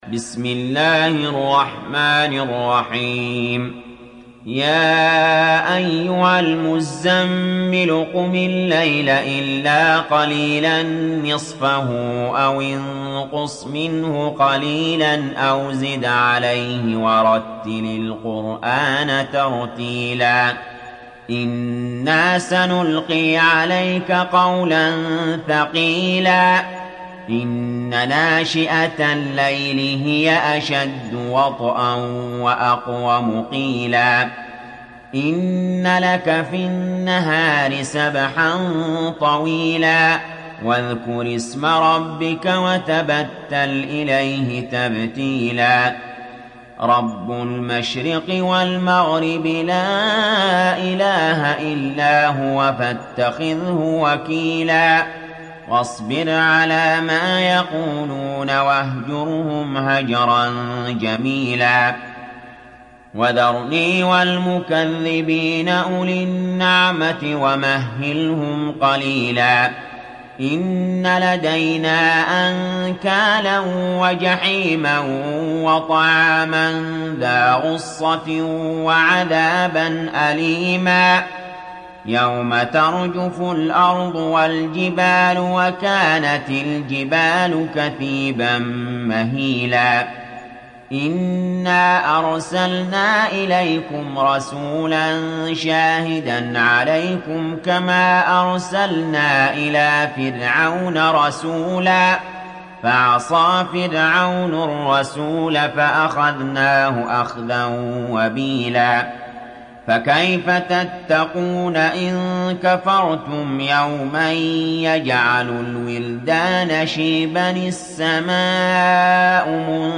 تحميل سورة المزمل mp3 بصوت علي جابر برواية حفص عن عاصم, تحميل استماع القرآن الكريم على الجوال mp3 كاملا بروابط مباشرة وسريعة